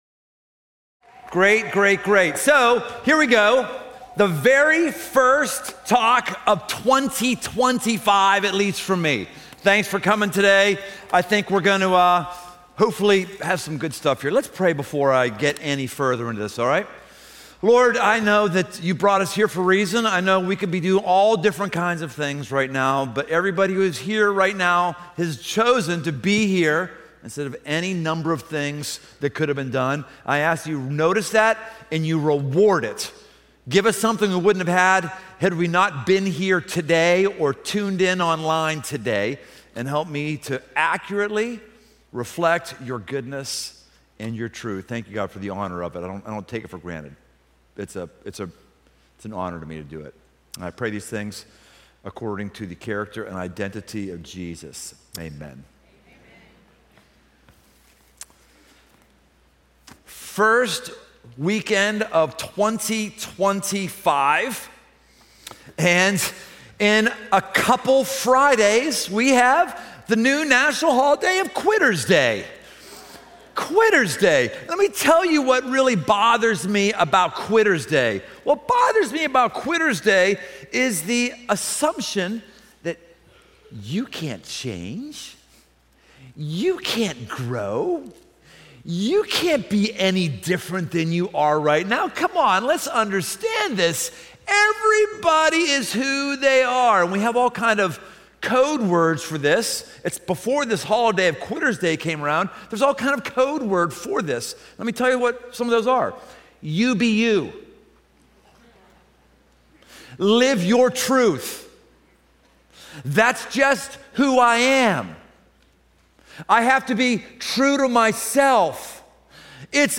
Come experience a live, improv, off-the-cuff style teaching from our Sr. Pastor